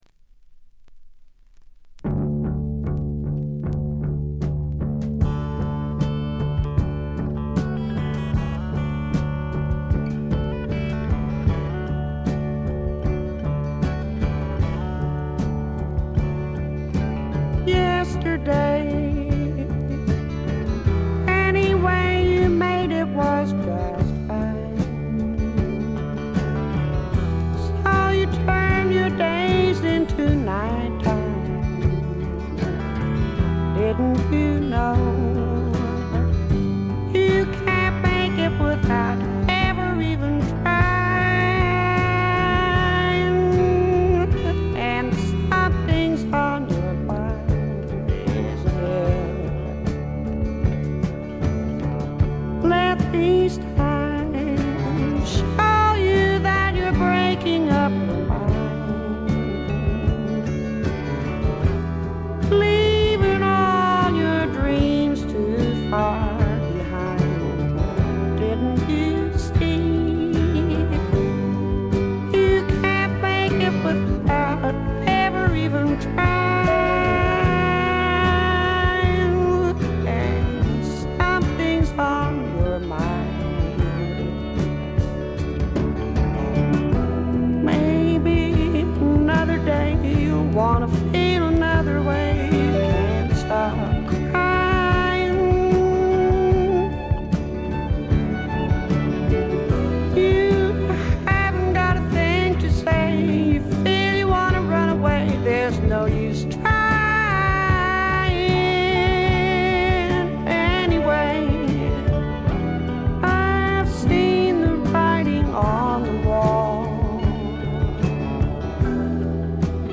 静音部での微細なチリプチが少しだけ。
試聴曲は現品からの取り込み音源です。
Vocals, Banjo, 12 String Guitar